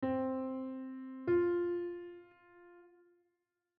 Perfect 4th
C-Perfect-Fourth-Interval-S1.wav